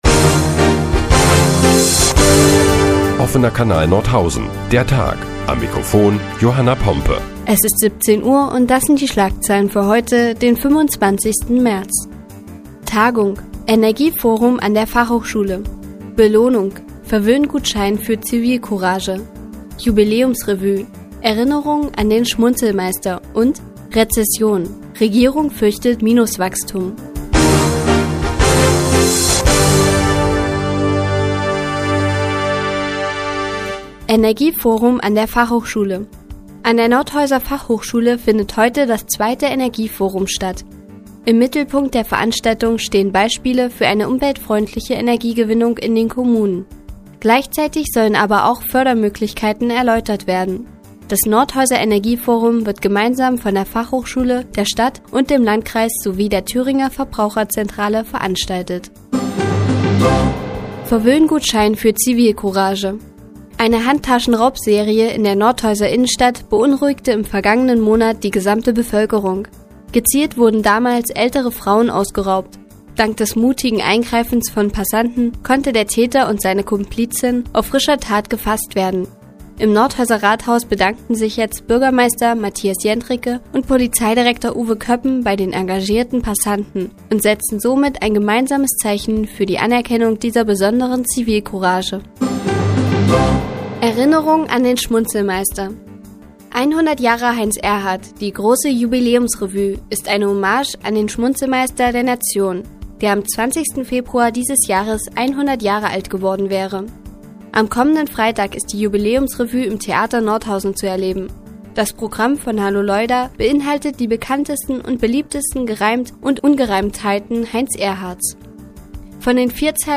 Die tägliche Nachrichtensendung des OKN ist nun auch in der nnz zu hören. Heute geht es unter anderem um engagierte Bürger und ein Hommage an den Schmunzelmeister.